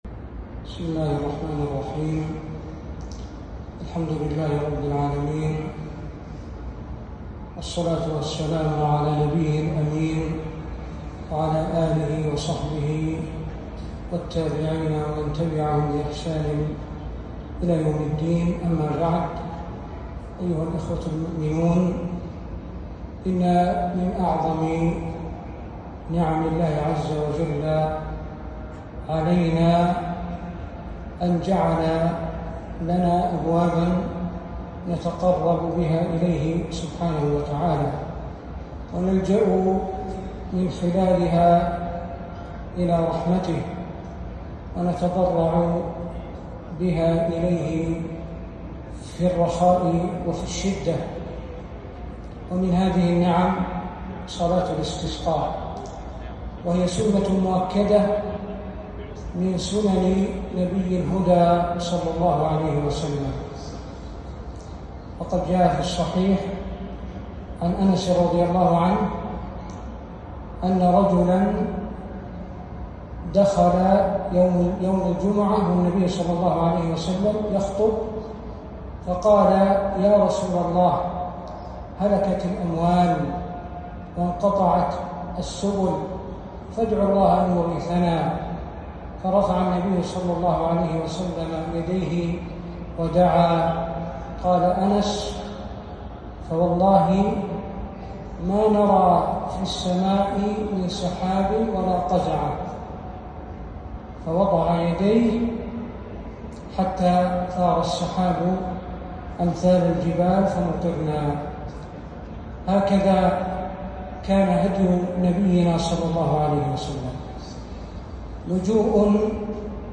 كلمة تذكيرية